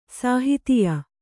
♪ sāhitiya